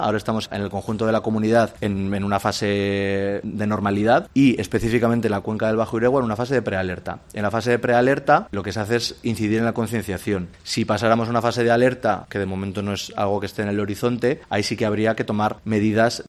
El consejero de Sostenibilidad y Transición Ecológica ha anunciado que mañana miércoles se reunirán con la Confederación Hidrográfica del Ebro, que es la que tiene la potestad de declarar las diferentes fases y las medidas restrictivas a aplicar en función de las mismas.